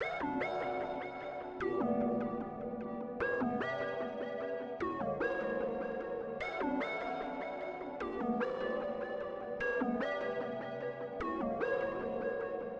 Uzi 150 bpm.wav